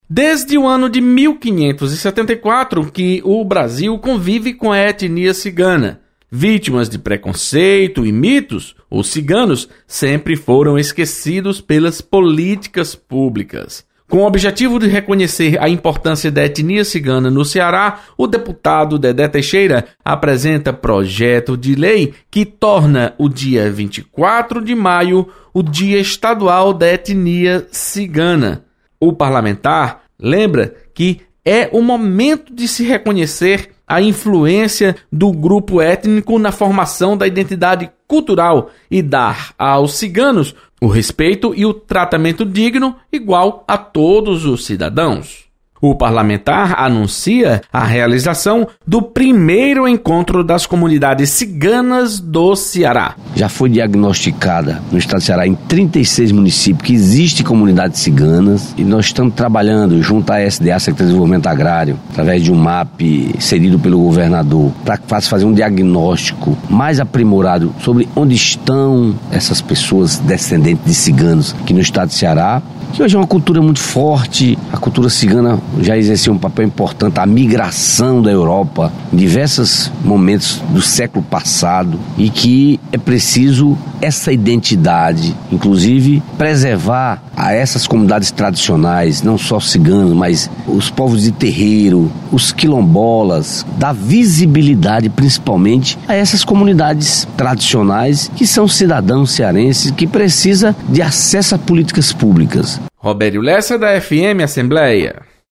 Deputado Dedé Teixeira ressalta importância da cultura cigana. Repórter